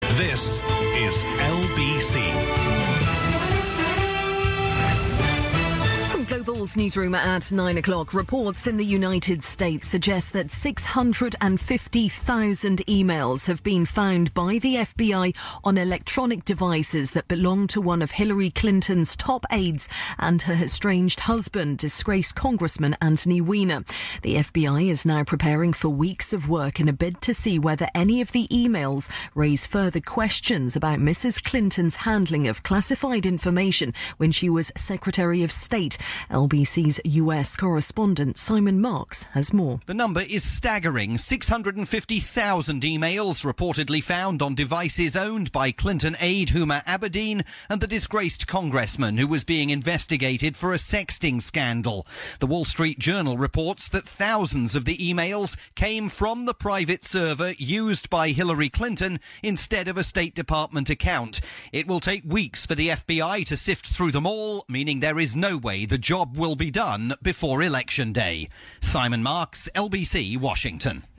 Breaking news about the FBI's discovery of a reported 650,000 trove of e-mails on electronic devices owned by Clinton aide Huma Abedin and disgraced former New York Congressman Anthony Weiner.